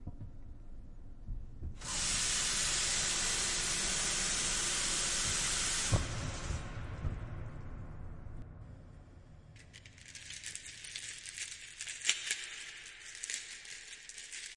现场记录 " 拉面。雨
描述：拉面的声音被编辑成听起来像雨
Tag: 拉面